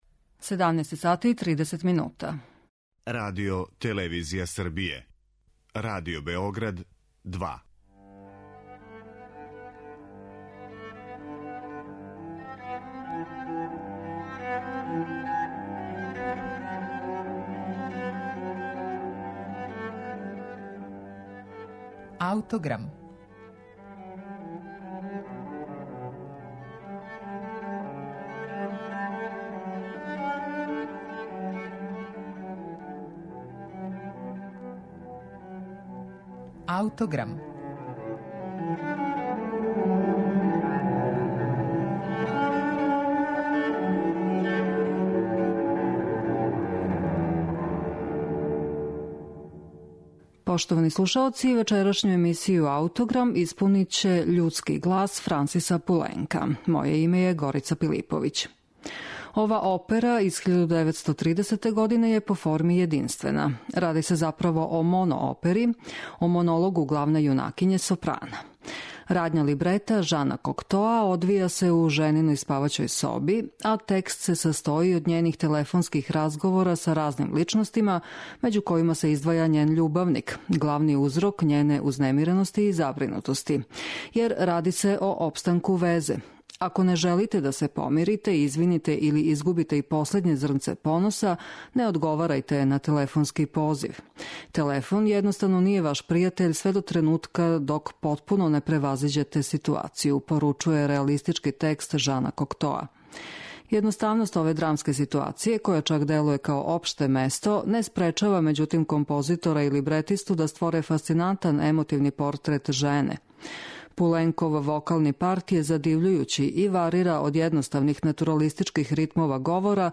То је врло занимљиво остварење, писано за само једну певачицу. Радња либрета Жана Коктоа одвија се у жениној спаваћој соби, а текст се састоји од њених телефонских разговора са разним личностима, међу којима се издваја њен љубавник, главни узрок њене узнемирености и забринутости.